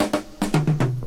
Doo Wop Fill.wav